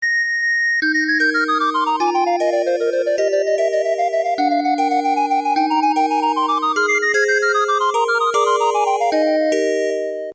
リコーダー